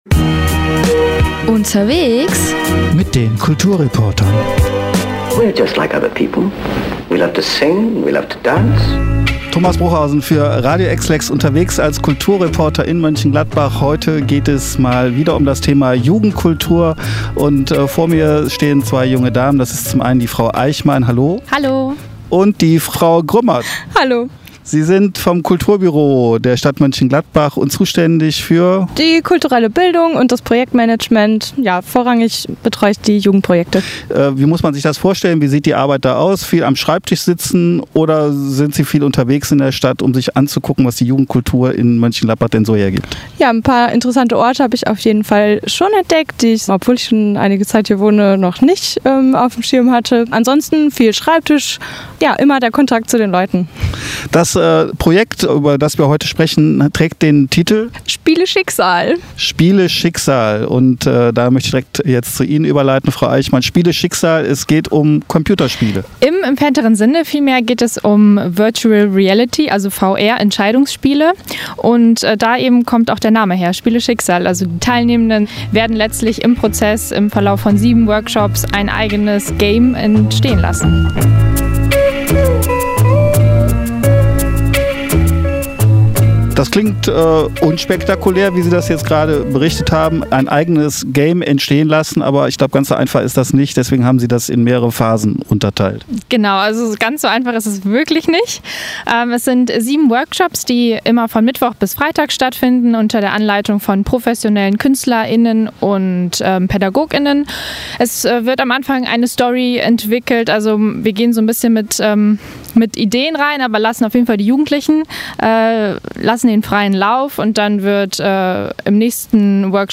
Interview-Spiele-Schicksal-Komplett-TB-KB_WEB.mp3